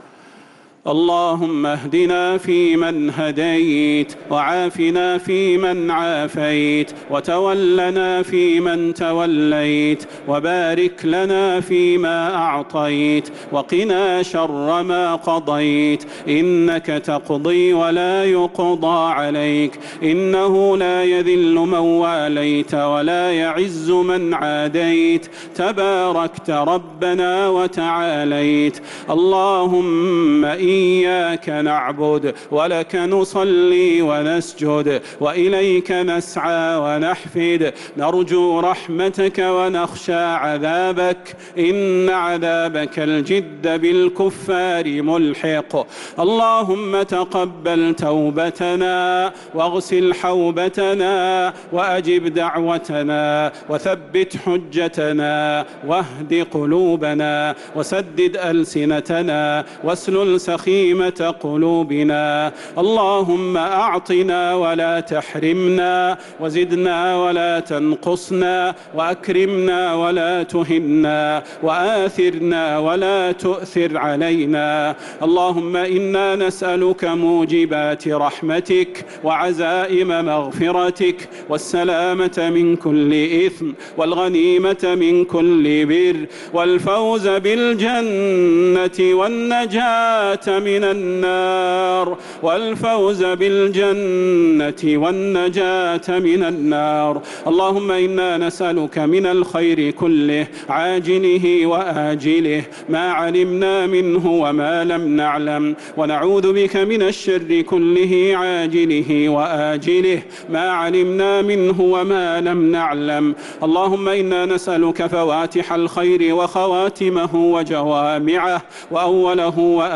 دعاء القنوت ليلة 9 رمضان 1446هـ | Dua 9th night Ramadan 1446H > تراويح الحرم النبوي عام 1446 🕌 > التراويح - تلاوات الحرمين